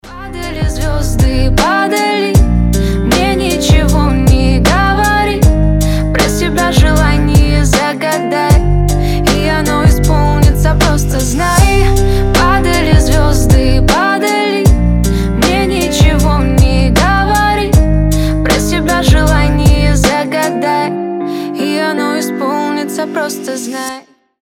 гитара
красивые
женский голос
спокойные